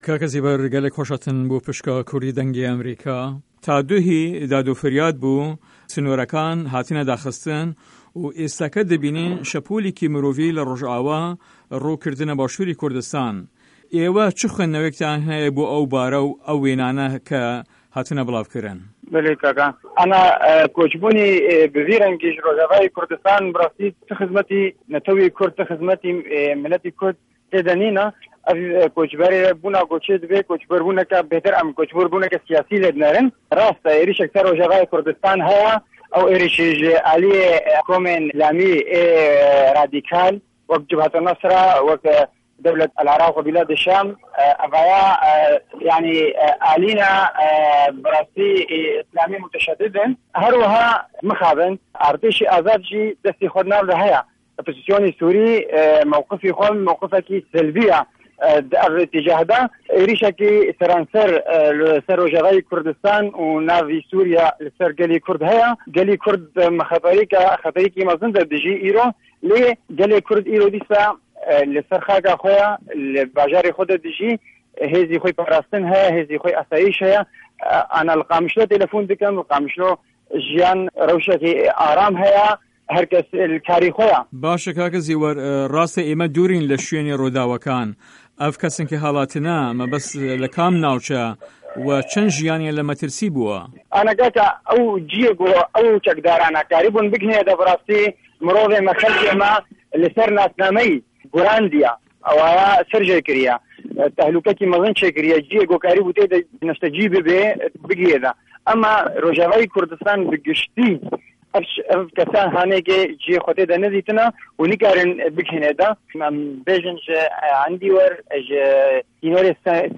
سوریا - گفتوگۆکان